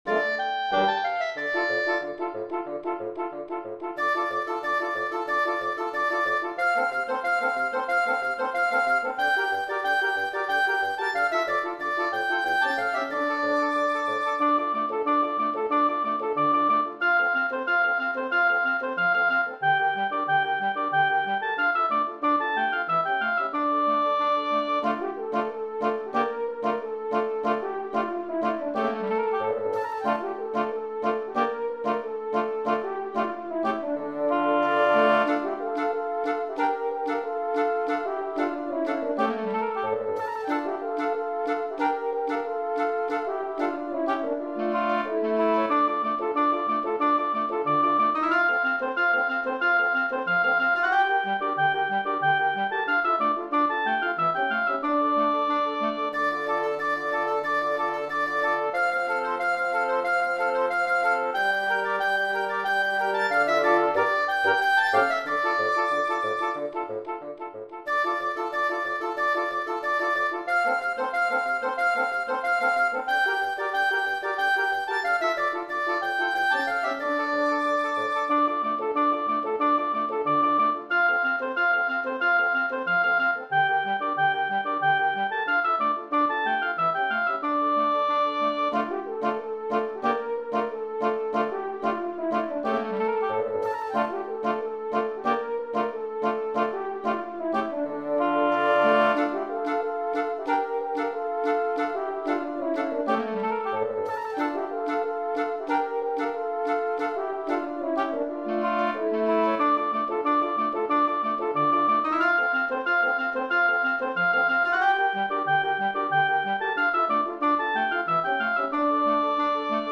Composer: Traditional Jewish
Voicing: Woodwind Quintet